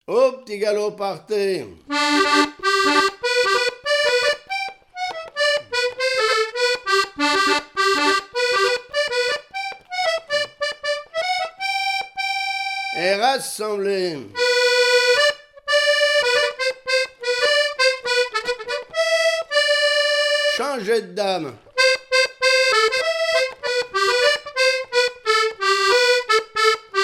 danse : quadrille : petit galop
Répertoire à l'accordéon diatonique
Pièce musicale inédite